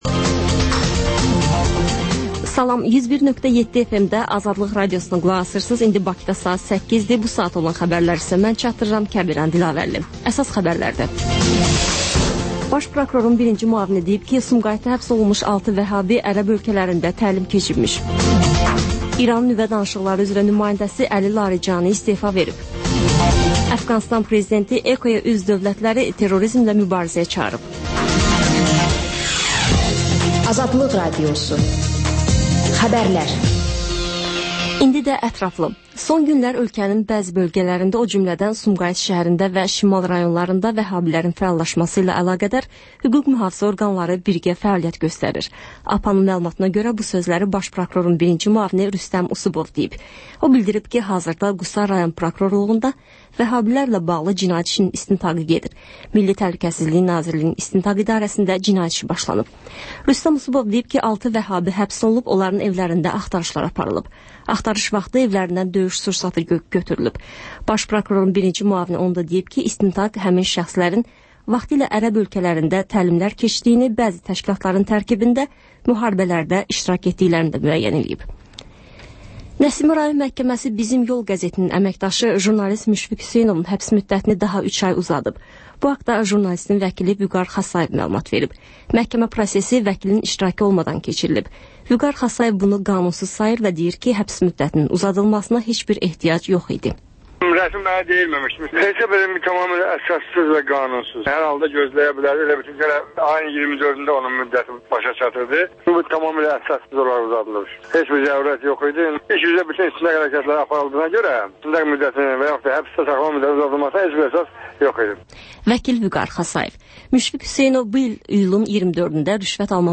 Xəbərlər, ardınca PANORAMA verilişi: Həftənin aktual mövzusunun müzakirəsi.